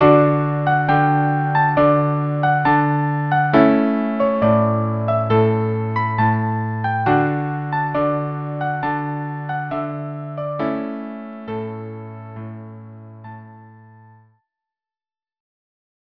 Instrumentation: Violin 1; Violin 2; Viola; Cello